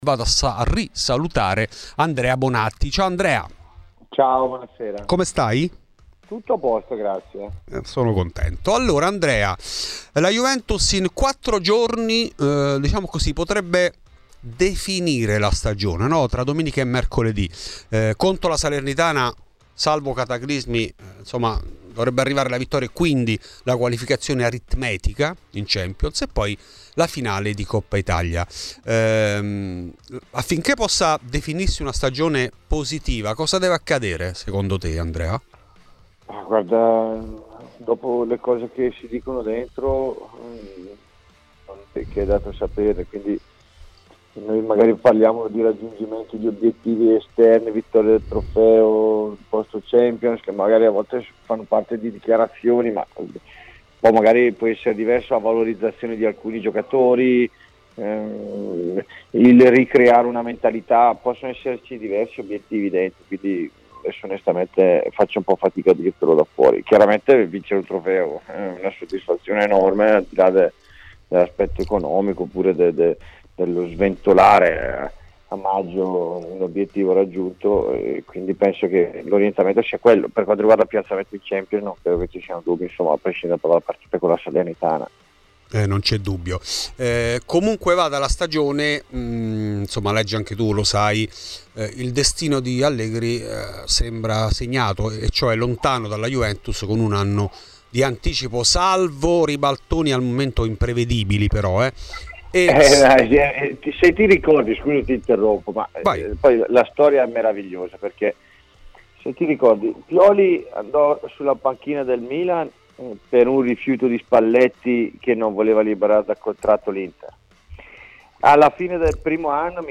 Nel podcast l'intervento integrale